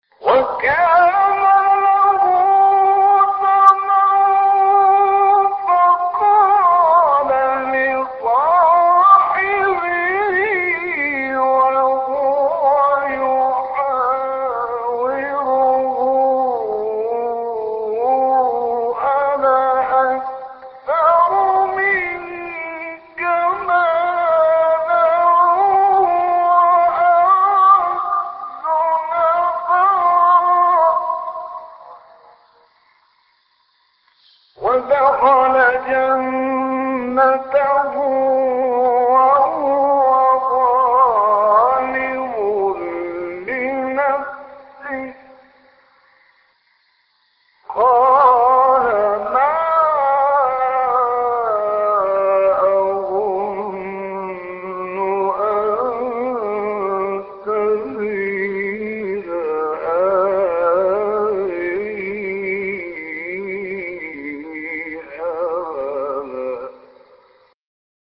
غلوش ـ مقام الحجاز - لحفظ الملف في مجلد خاص اضغط بالزر الأيمن هنا ثم اختر (حفظ الهدف باسم - Save Target As) واختر المكان المناسب